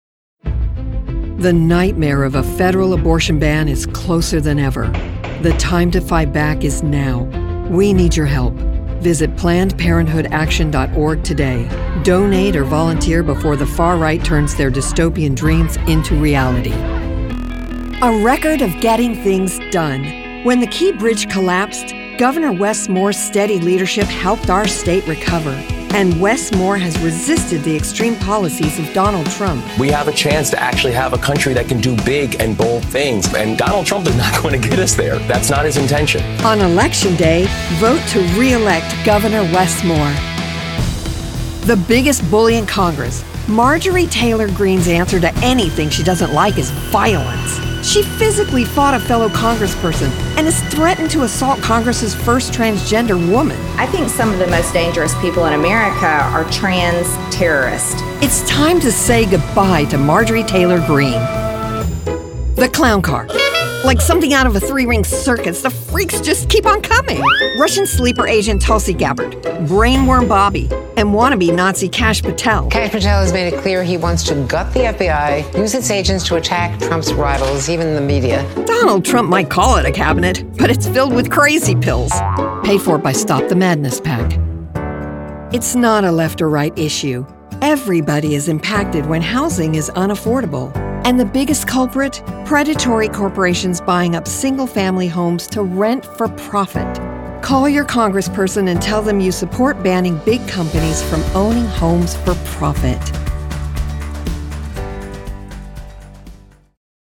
Versatile, friendly, confident.
Political Demo
I have a state-of-the-art professional home studio.